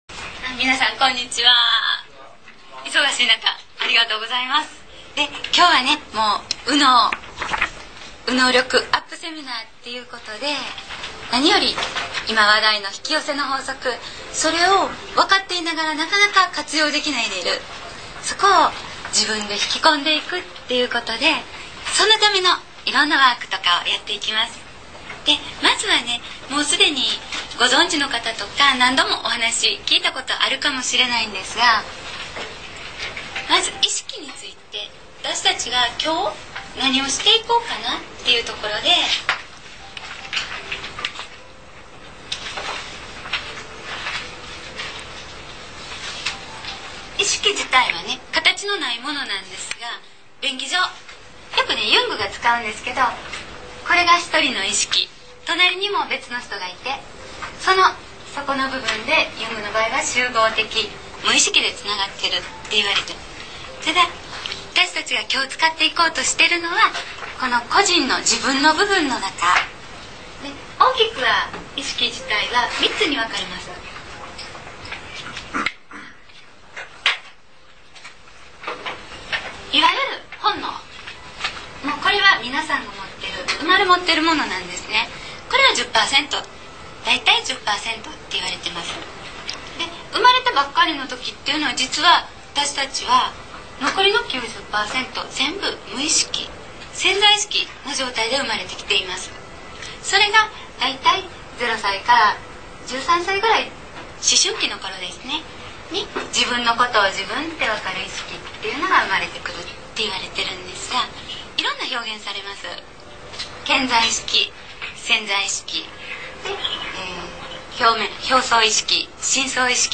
２００８年　６月　８日　クレオ大阪中央にて収録